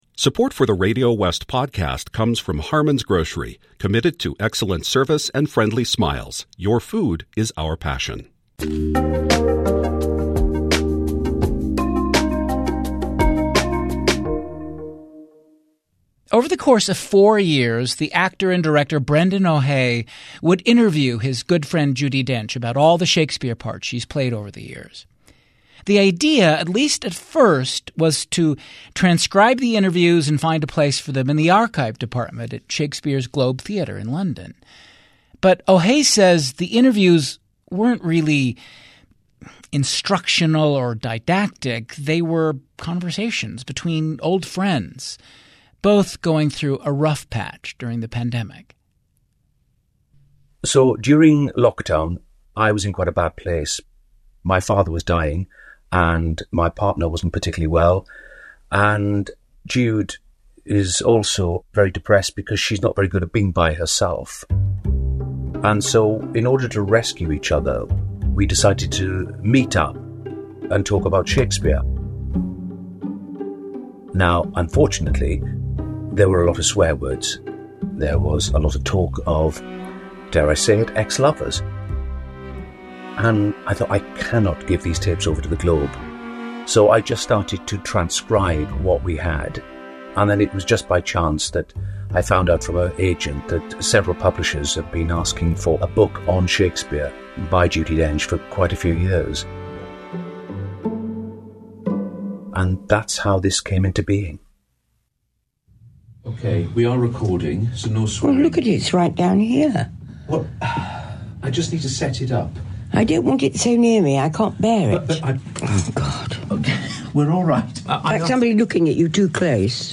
Join writers, filmmakers, scientists and others on RadioWest: A show for the wildly curious.